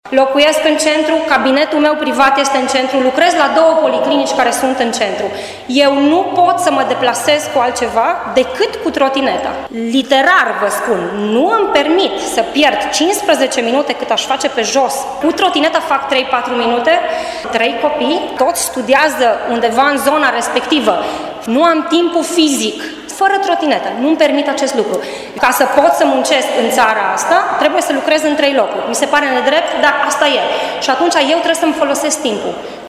Un medic participant la dezbatere a susținut o pledoarie în favoarea permiterii trotinetelor.
Dezbatere-PMT-cetatean-2.mp3